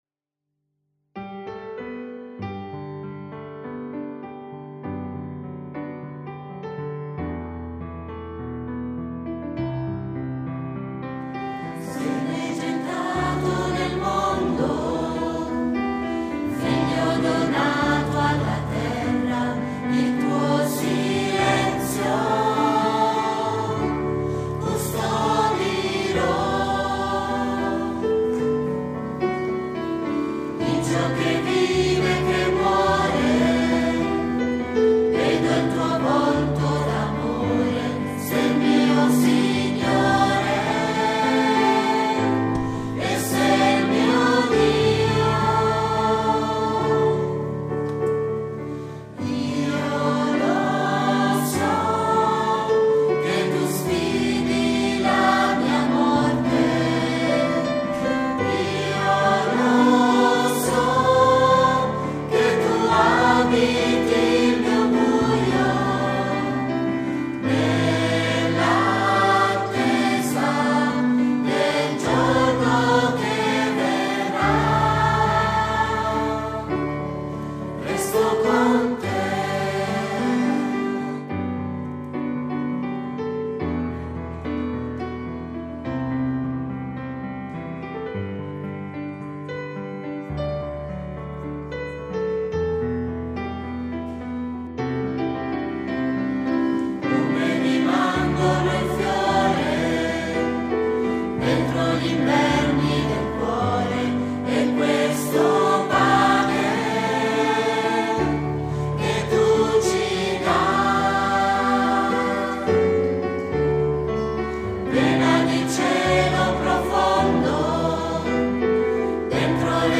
restocontecoro.mp3